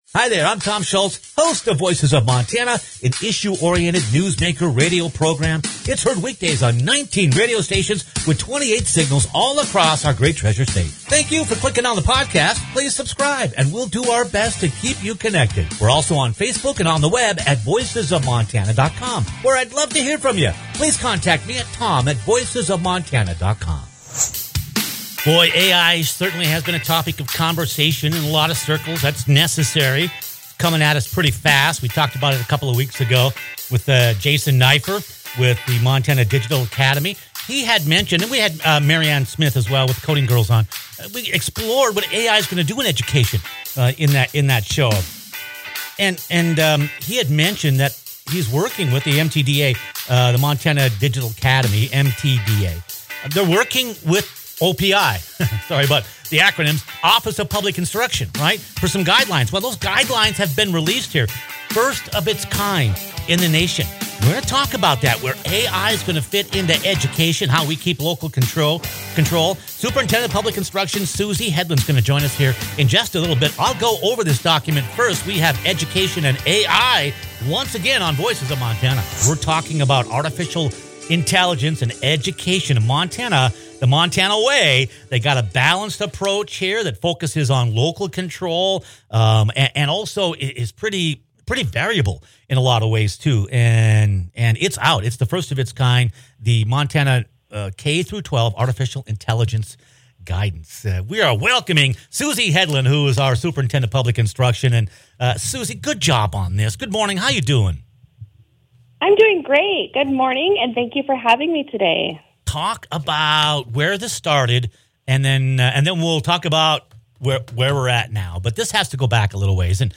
The technology can be a great benefit, but it also poses its own set of problems that can hamper education. Montana Superintendent of Public Instruction Susie Hedalen joins us to unveil the state’s new K–12 Artificial Intelligence Guidelines — the first of its kind